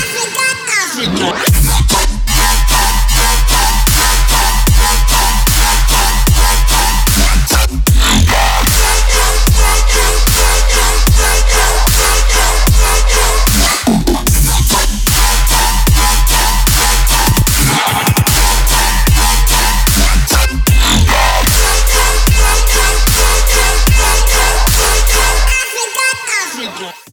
• Качество: 320, Stereo
громкие
жесткие
Electronic
Dubstep
riddim